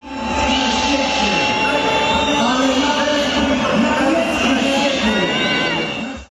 Minister obrony był tam na charytatywnym meczu koszykówki, w którym drużyna Marcina Gortata zmierzyła się z reprezentacją Wojska Polskiego.
Przed pierwszym gwizdkiem – gwizdów było więcej, kiedy szef MONu stanął przy mikrofonie.